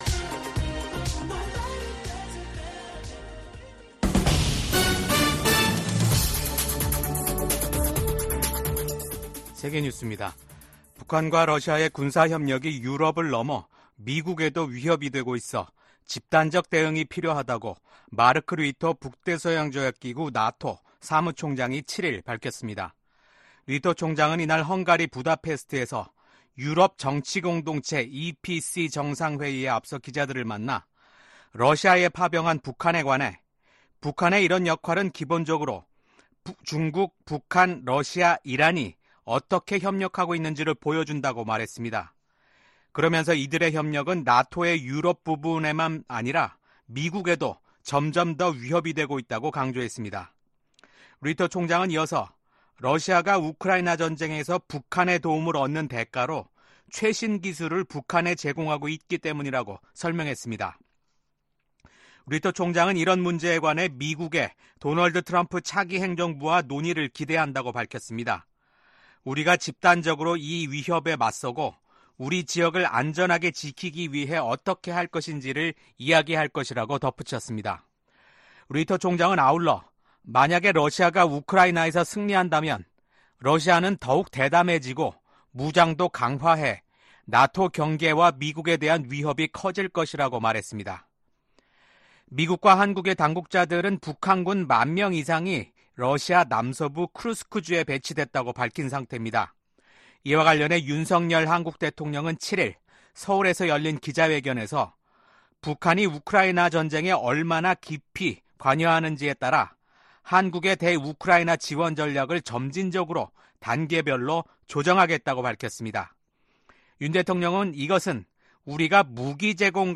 VOA 한국어 아침 뉴스 프로그램 '워싱턴 뉴스 광장'입니다. 제47대 대통령 선거에서 도널드 트럼프 전 대통령에게 패한 카멀라 해리스 부통령이 패배를 공개적으로 인정했습니다. 윤석열 한국 대통령은 도널드 트럼프 미국 대통령 당선인과 전화통화를 하고 양국의 긴밀한 협력관계 유지에 공감했습니다. 도널드 트럼프 대통령 당선인이 2기 행정부에서 첫 임기 때와 다른 대북 접근법을 보일 수도 있을 것으로 전문가들은 전망했습니다.